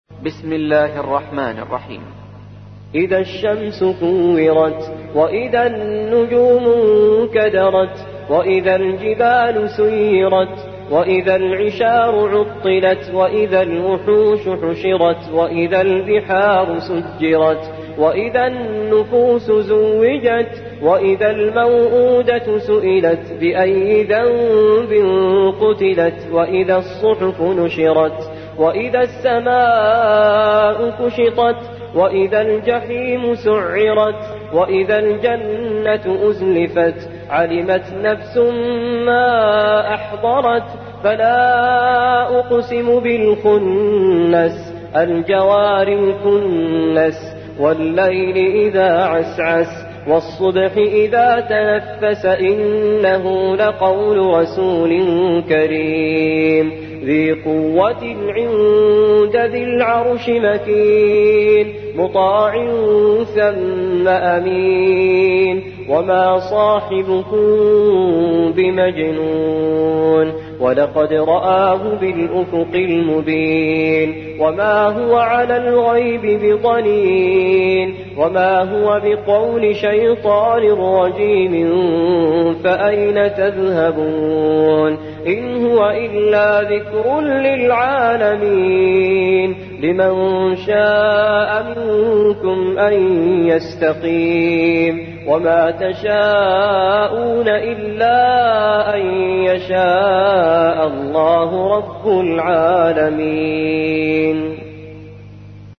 81. سورة التكوير / القارئ
القرآن الكريم